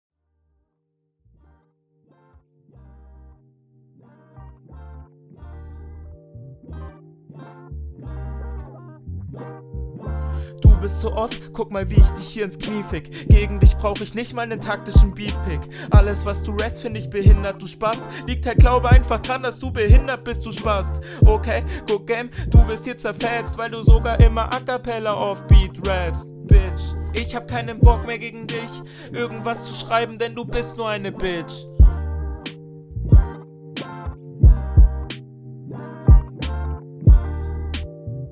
Erste richtige Runde - leider ziemlich Mittelmaß Ist auf dem Takt, reimt sich und geht …